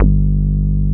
F.D. BASSE 3.wav